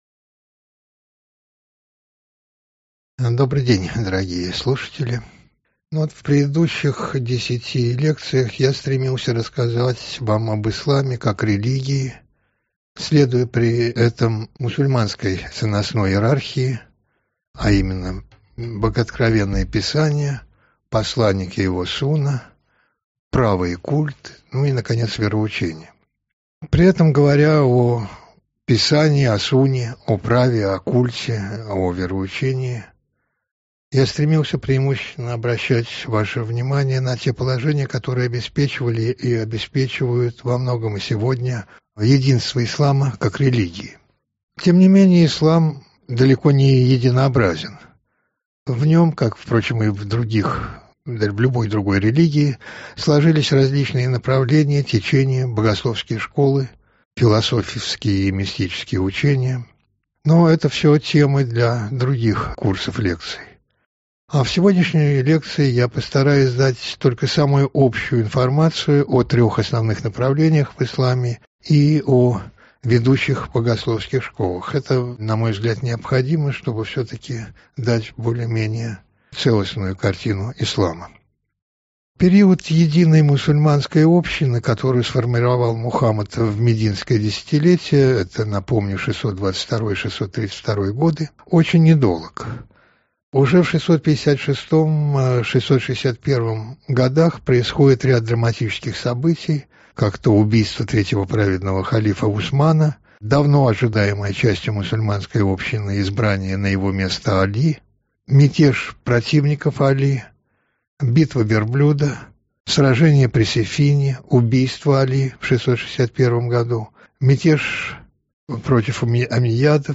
Аудиокнига Направления в исламе: суннизм, шиизм, хариджизм.